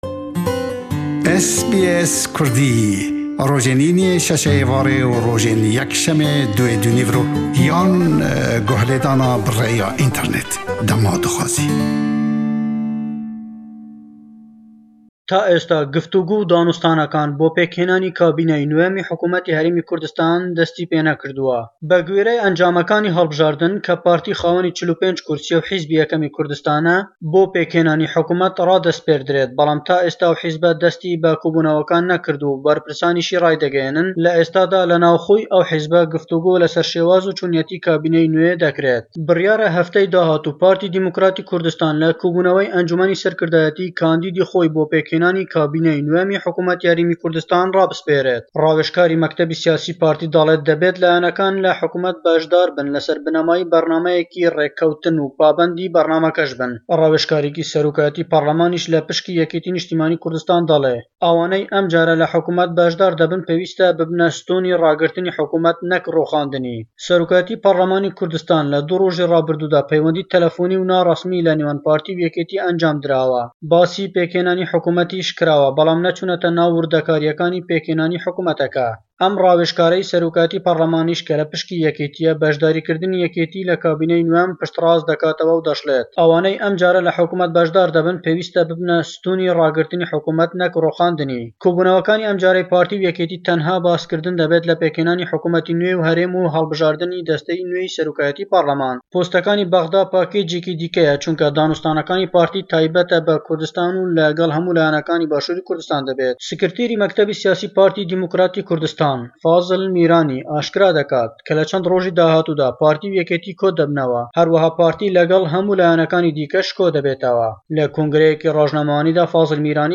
le Hewlêre we sebaret be tawtwêkan le nêwan layen sîyasêkan bo pêkhênanî hukûmetî nwêy HK, raport dekat. Wa dîyare PDK hukûmet le gell YNK pêkbihênin...